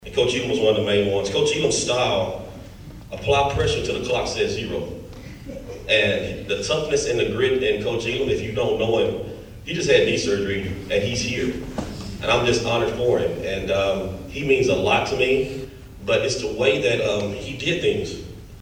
The Brenham Cub Football Hall of Honor welcomed four new members into their ranks before a packed house at the Blinn College Student Center this (Friday) afternoon.